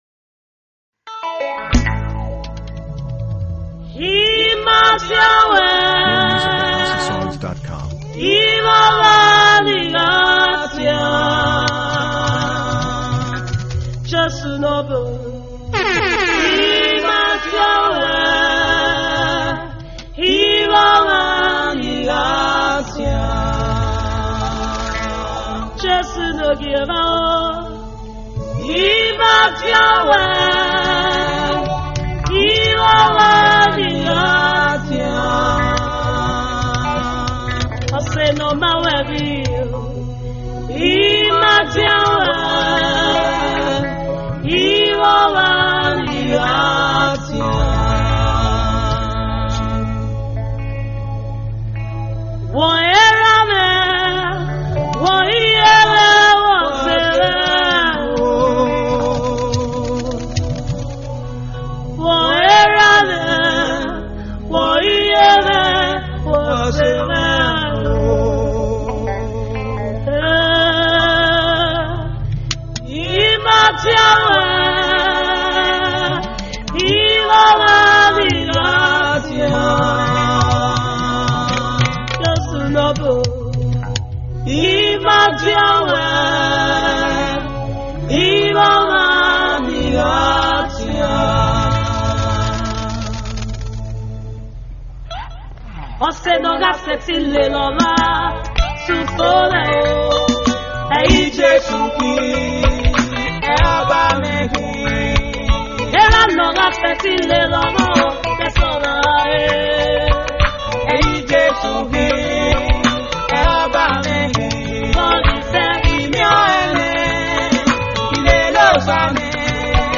Gospel